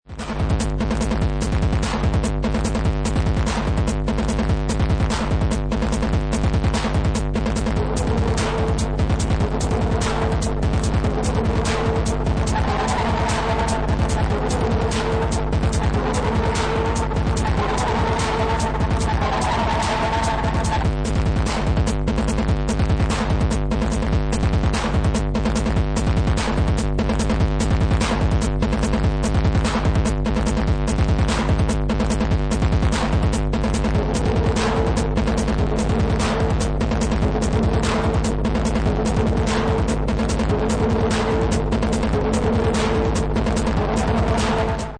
Techno Detroit Acid